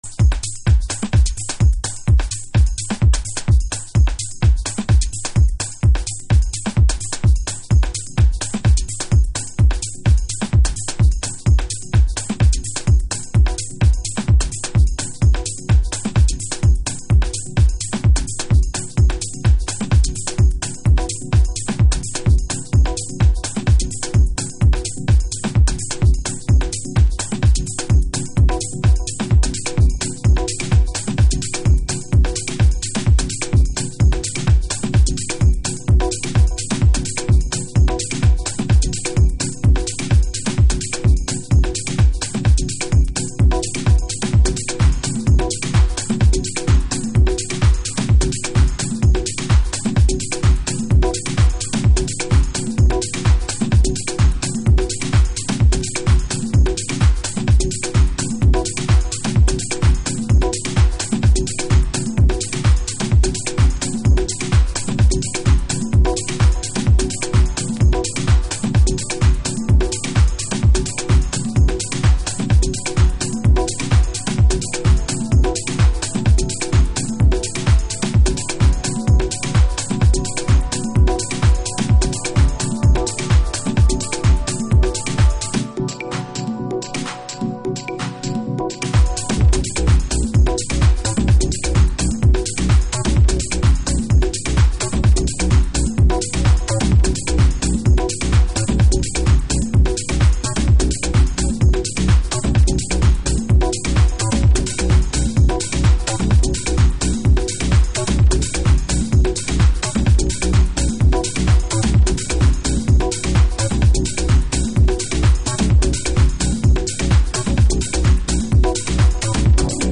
House / Techno
ローカルでテクノが生まれていたスコットランド生まれ（現オランダ在住）による直球ポスト・デトロイトテクノトラック。